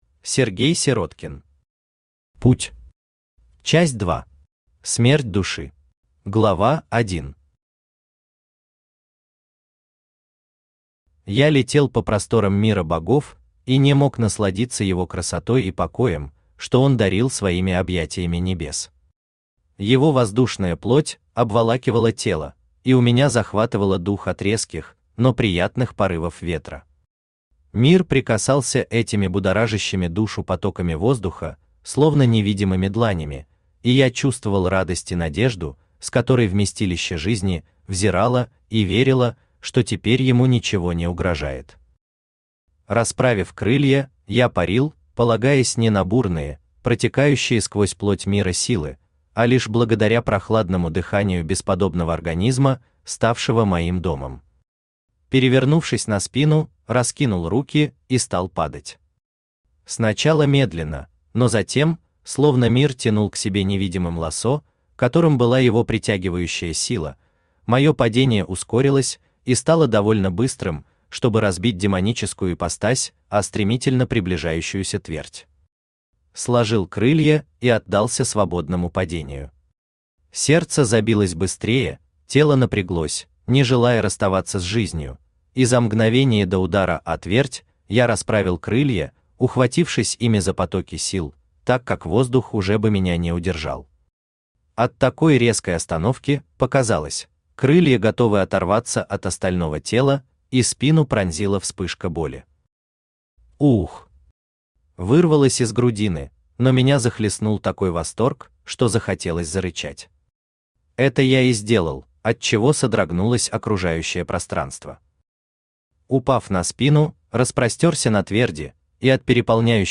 Аудиокнига Путь. Часть 2. Смерть души | Библиотека аудиокниг
Смерть души Автор Сергей Павлович Сироткин Читает аудиокнигу Авточтец ЛитРес.